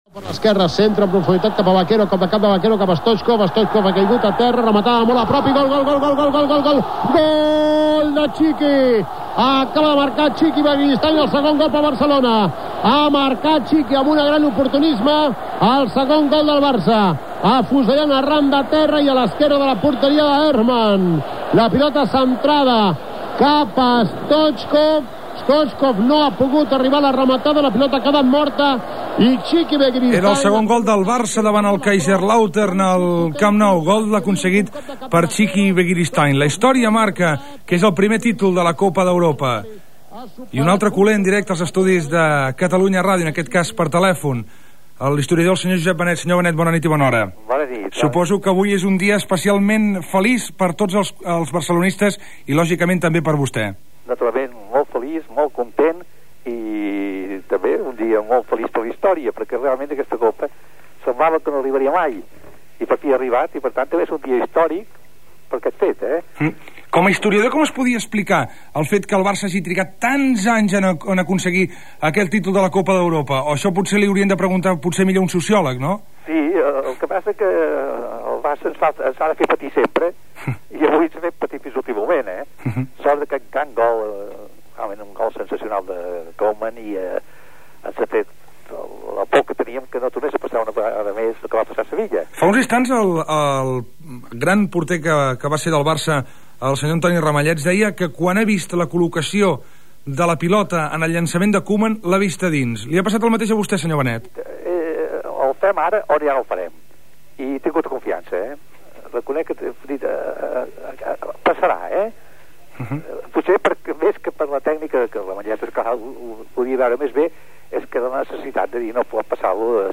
Fragment del programa especial realitzat en motiu de la victòria del Futbol Club Barcelona a Wembley, el primer títol europeu aconseguit per l'equip barceloní. Intervenció de l'historiador Josep Benet.
Esportiu